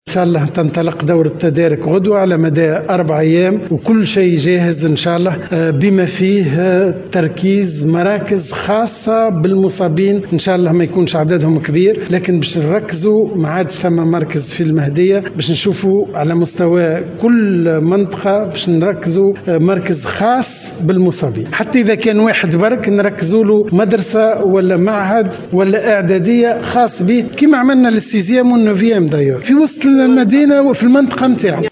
أكد وزير التربية فتحي السلاوتي في تصريح لمراسل الجوهرة "اف ام", أن دورة المراقبة لباكالوريا 2021 ستنطلق يوم غدا على مدى 4 أيام.